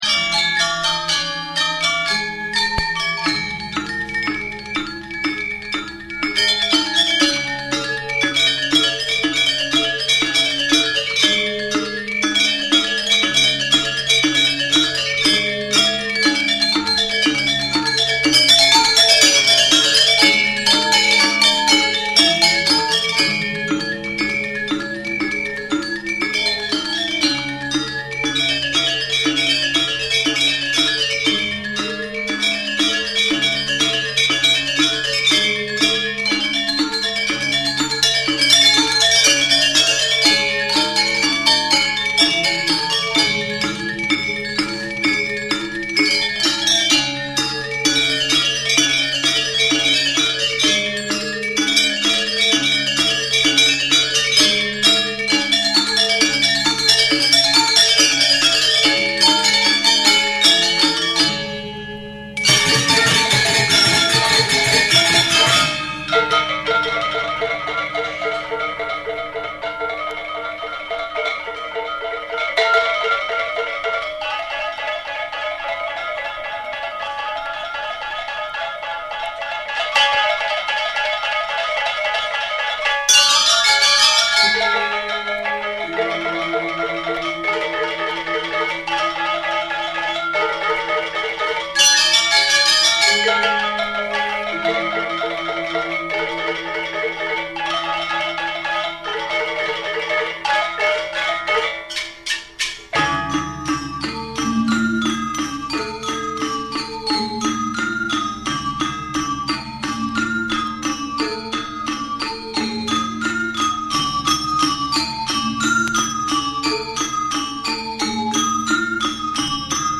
WORLD